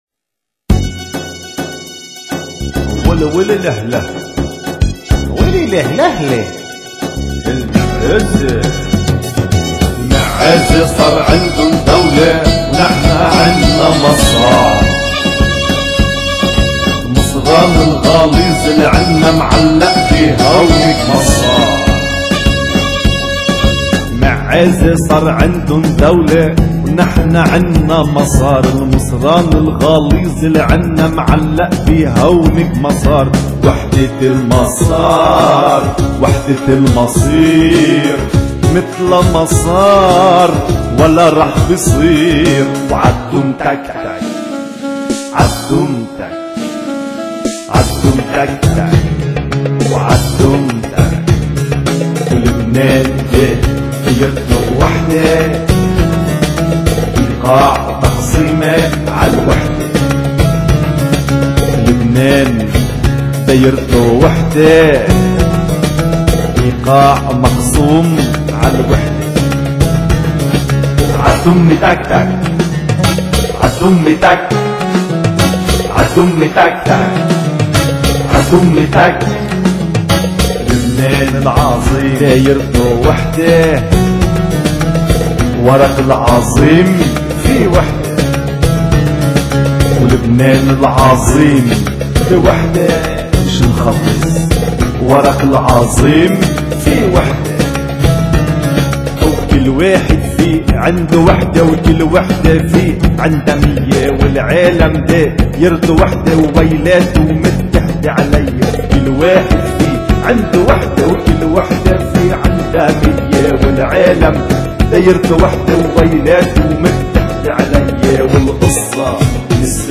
انشاد منفرد                       ل وحدة المسار